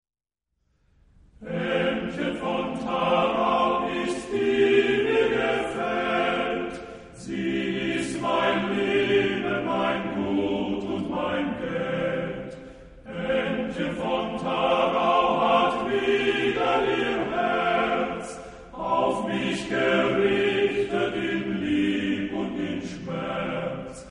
Genre-Stil-Form: romantisch ; weltlich ; Lied
Chorgattung: TTBB  (4 Männerchor Stimmen )
Tonart(en): Es-Dur